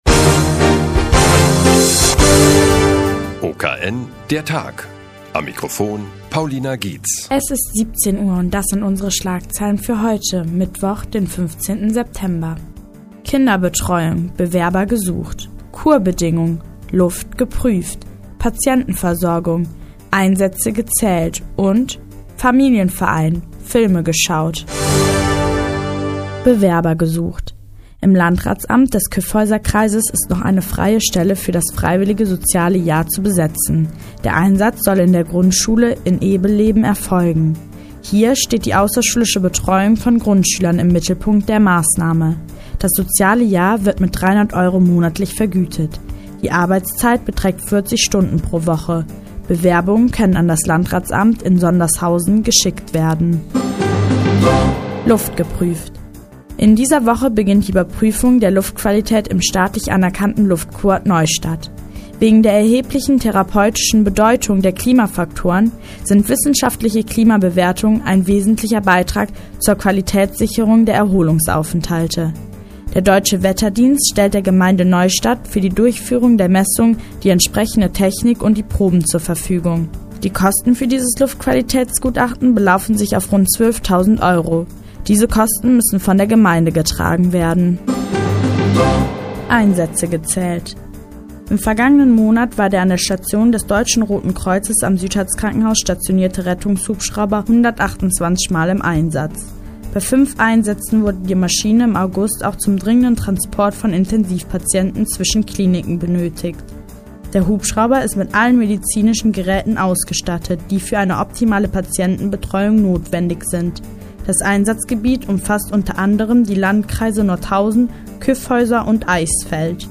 15.09.2010, 16:15 Uhr : Seit Jahren kooperieren die nnz und der Offene Kanal Nordhausen. Die tägliche Nachrichtensendung des OKN ist nun in der nnz zu hören.